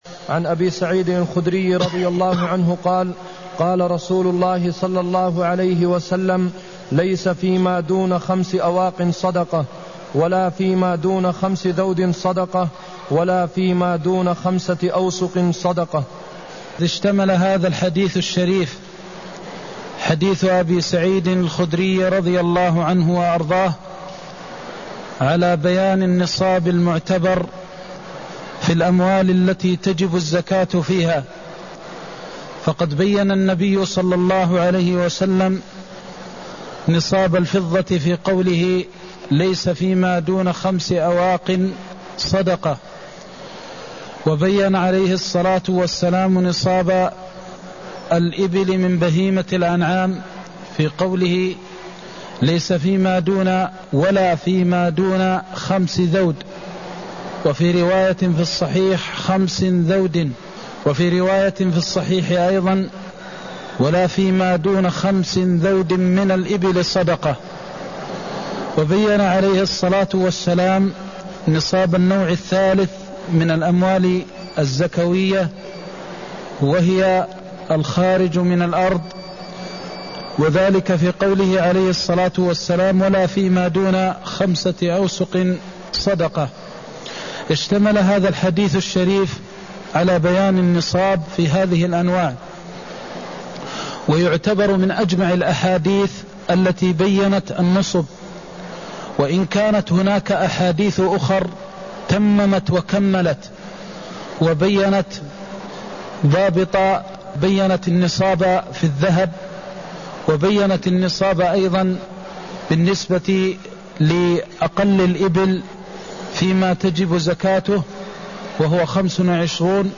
المكان: المسجد النبوي الشيخ: فضيلة الشيخ د. محمد بن محمد المختار فضيلة الشيخ د. محمد بن محمد المختار بيان أنصبة الزكاة (163)